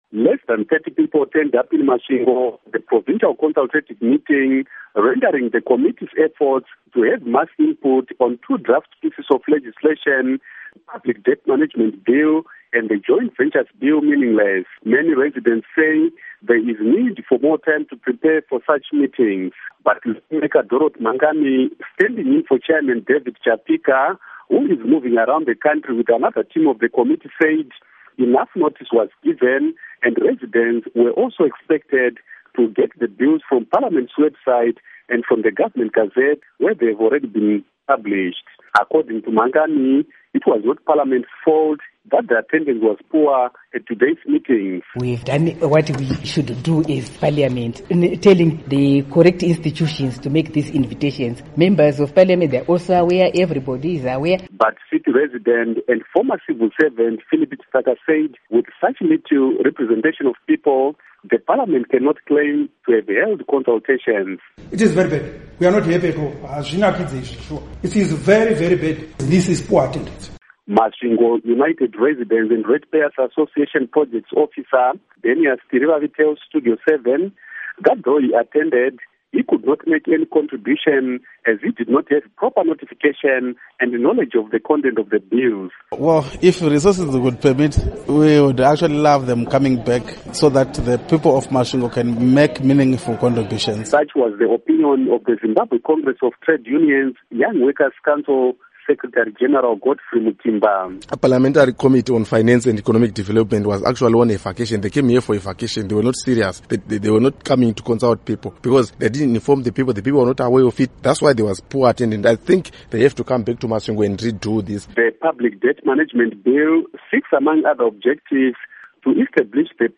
Report on Parliamentary Bills